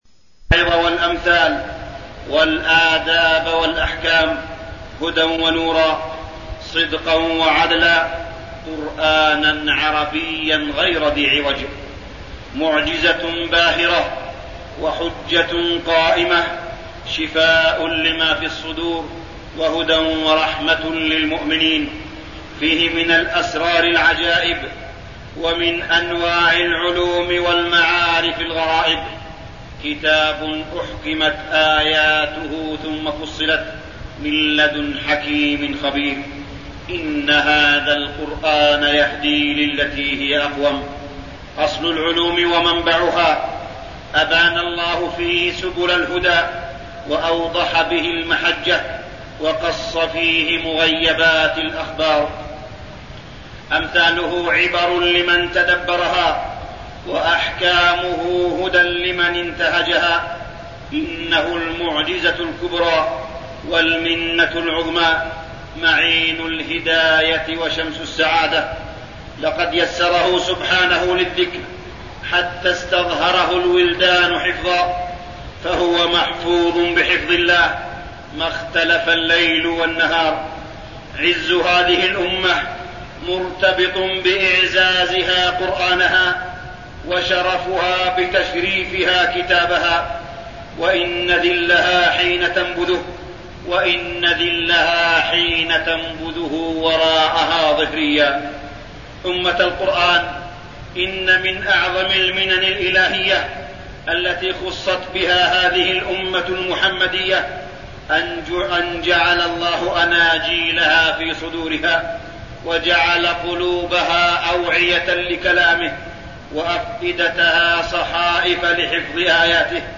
تاريخ النشر ٨ رمضان ١٤٠٩ هـ المكان: المسجد الحرام الشيخ: معالي الشيخ أ.د. صالح بن عبدالله بن حميد معالي الشيخ أ.د. صالح بن عبدالله بن حميد فضائل القرآن